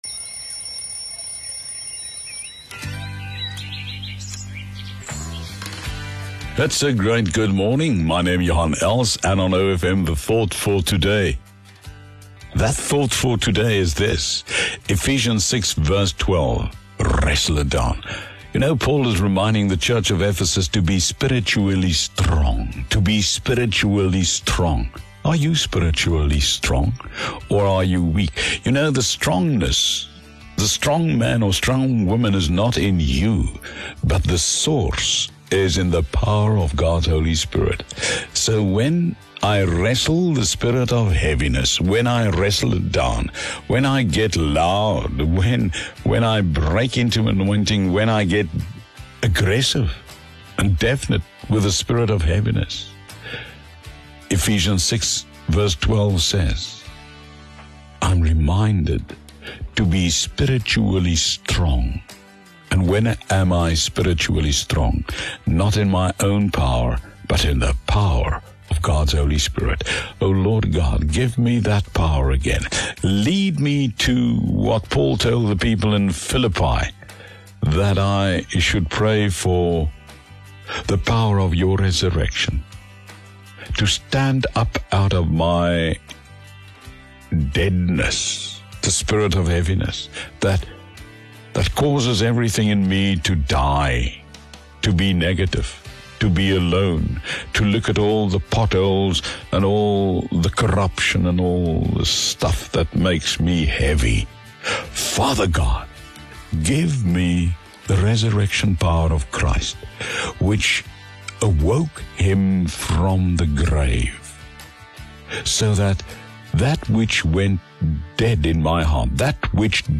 Thought for the Day as heard on OFM on 12 March 2022.